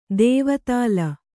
♪ dēvatāla